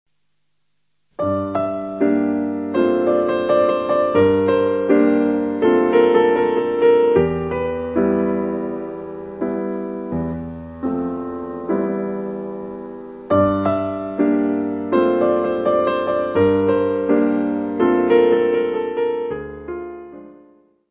Music Category/Genre:  Classical Music